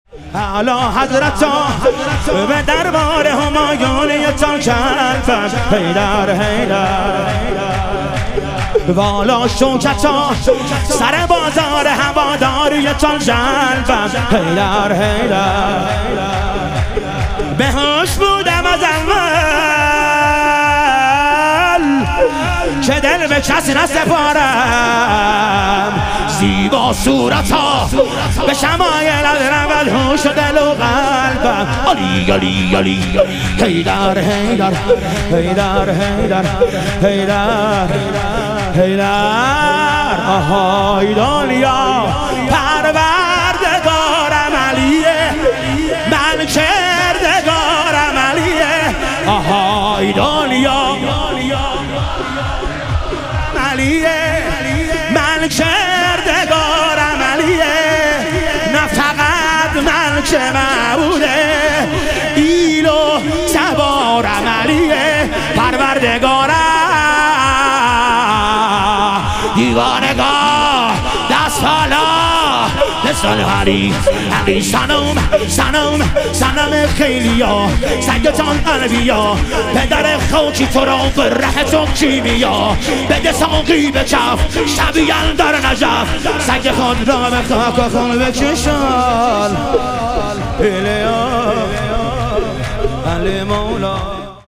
ظهور وجود مقدس رسول اکرم و امام صادق علیهم السلام - شور